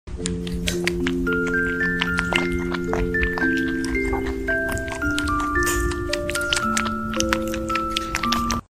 Cute bunny eating a strawberry sound effects free download